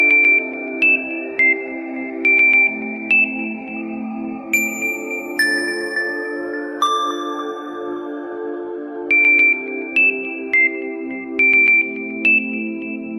Kategorien Sms Töne